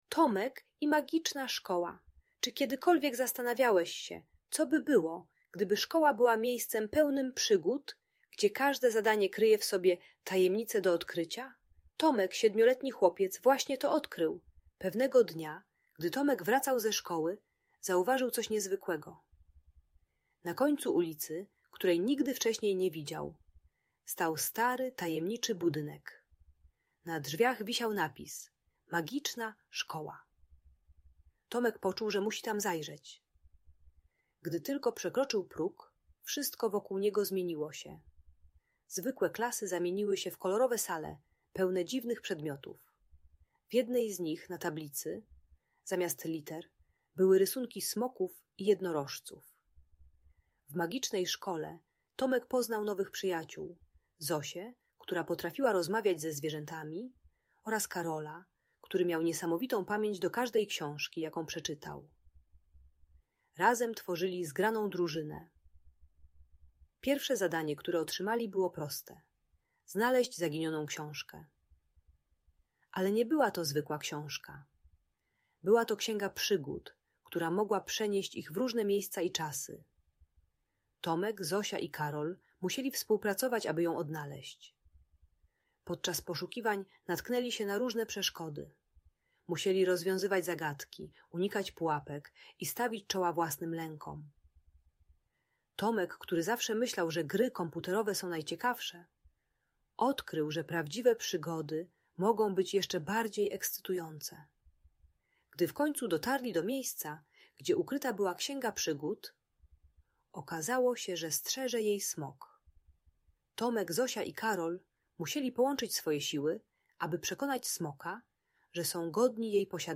Tomek i Magiczna Szkoła - Bajki Elektronika | Audiobajka